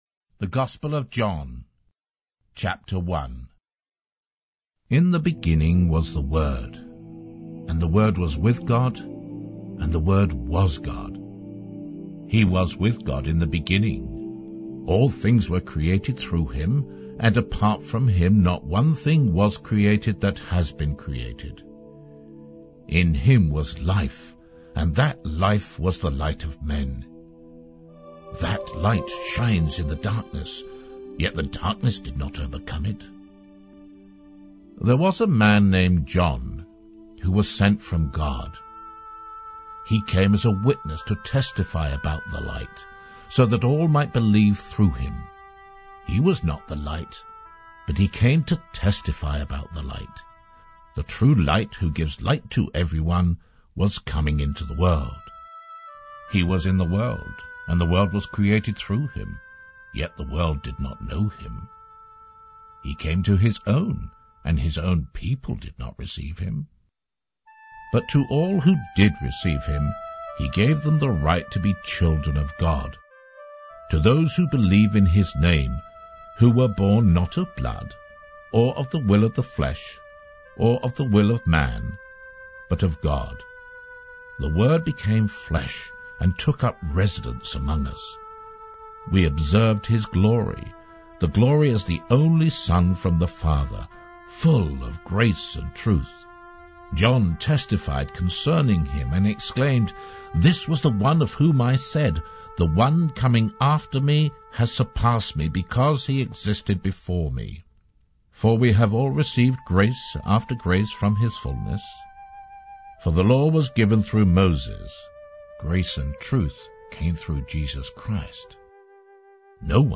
• Word for word narration
hcsb-bible-2295-john-1.mp3